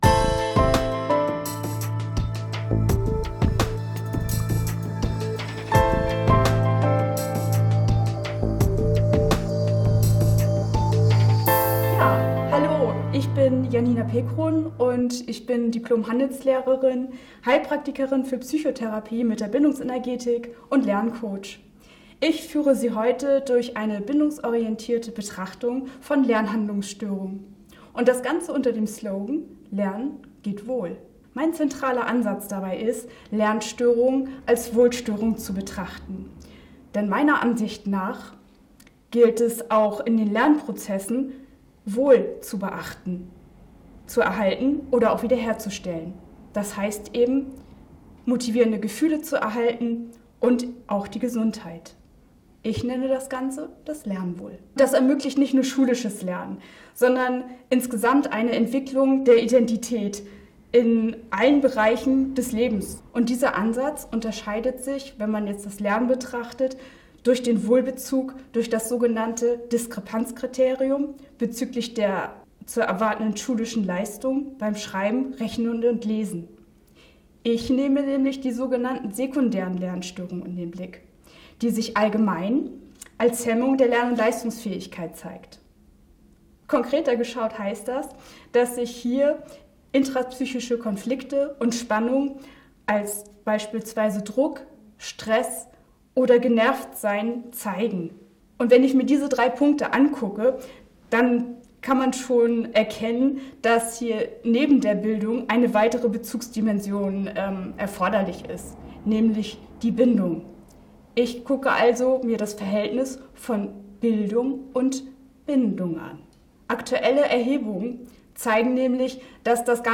Ein Vortrag zum Lernwohl von mir ist gleich hier: